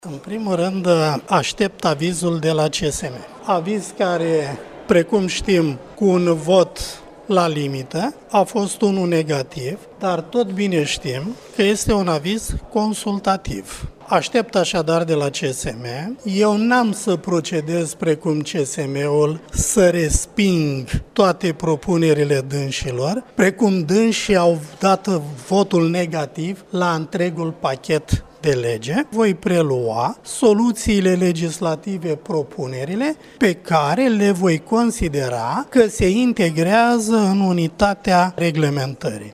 Legile Justiţiei vor suferi unele modificări conform unor propuneri care au venit din partea membrilor CSM. Votul negativ dat la limită în CSM are un caracter consultativ, a  declarat astăzi la Iaşi, Ministrul Justiţiei, Tudorel Toader: